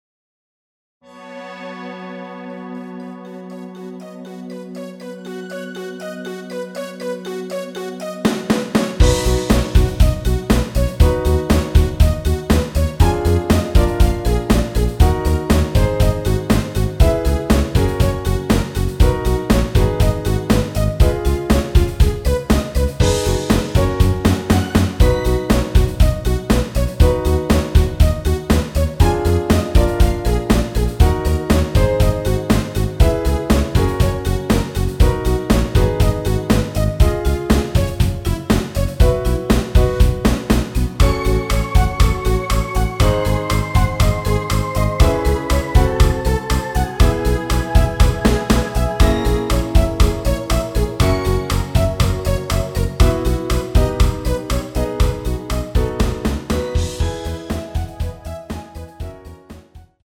엔딩이 페이드 아웃이라 가사의 마지막 까지후 엔딩을 만들어 놓았습니다.!
여자키에서(+1)더 올린 MR 입니다.(미리듣기 참조)
앞부분30초, 뒷부분30초씩 편집해서 올려 드리고 있습니다.
중간에 음이 끈어지고 다시 나오는 이유는